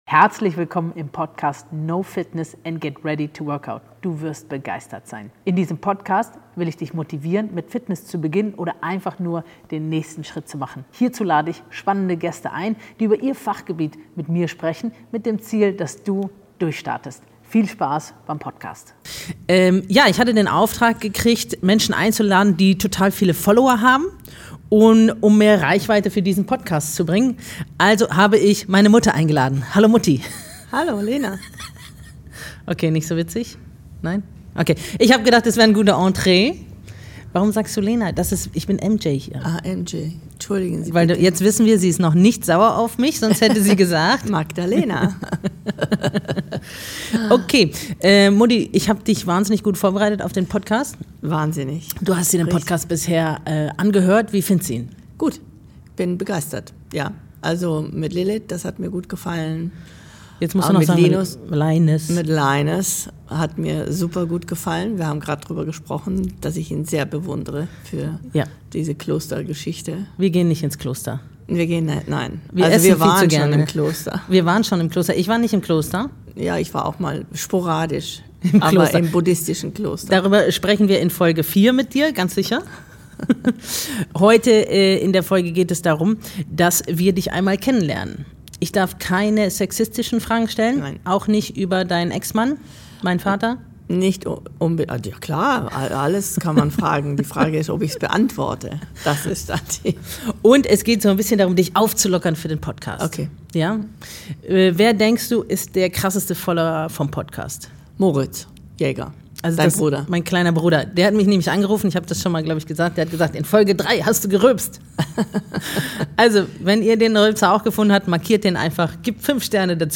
Es geht um witzige Anekdoten, Fehlstarts, Feuerwehreinsätze und die berühmten Whirlwannen, die keiner reinigen wollte. Eine ehrliche, emotionale und zugleich herrlich unterhaltsame Episode über Unternehmertum, Familie und den Ursprung eines der bekanntesten Clubs der Stadt.